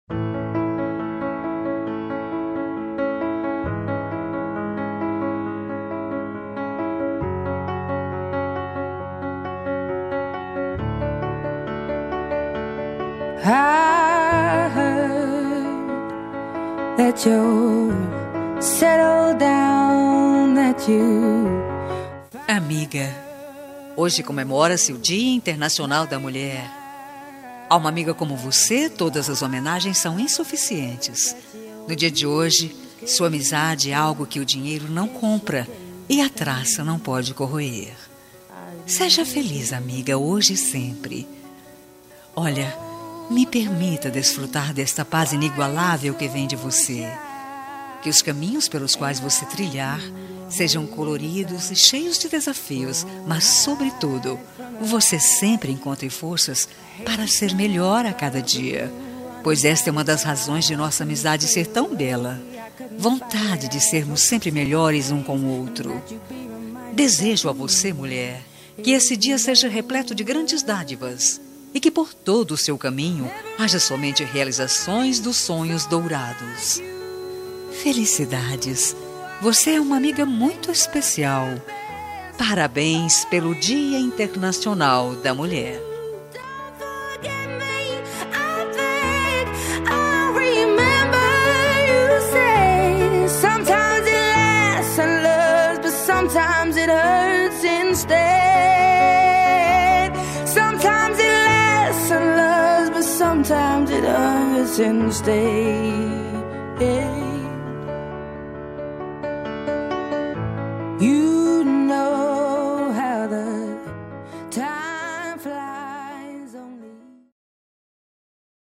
Dia das Mulheres Para Amiga – Voz Feminina – Cód: 5351